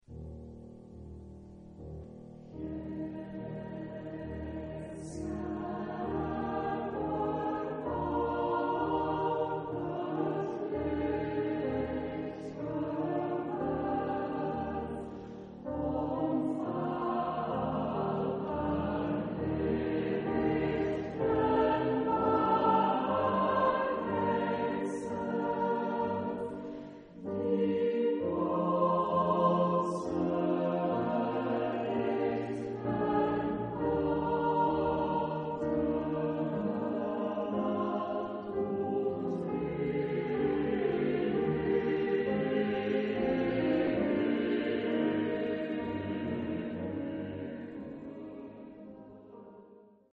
Epoque: 19th century
Genre-Style-Form: Canticle ; Sacred ; Romantic
Type of Choir: SATB  (4 mixed voices )
Instruments: Piano (1)
Tonality: E flat major